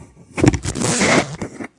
描述：设有皮夹克的短噪音。
Tag: 拉链 服装 皮革 装备 装甲